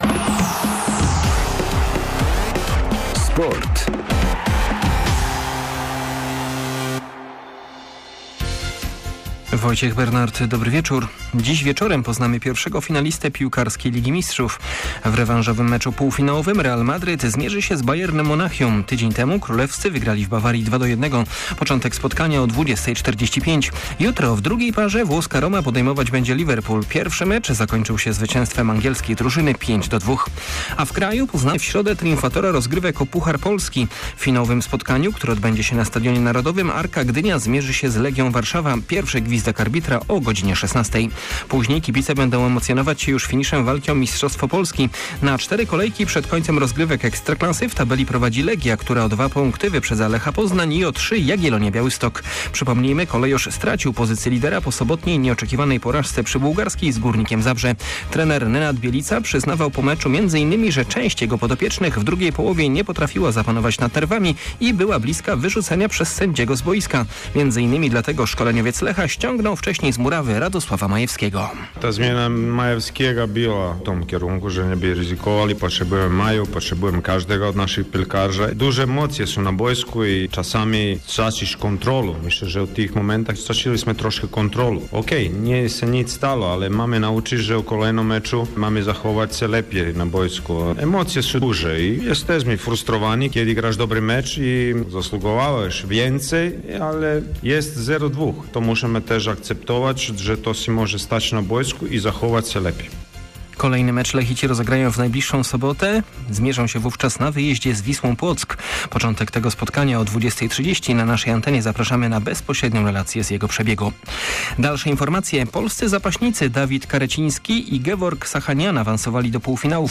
01.05 serwis sportowy godz. 19:05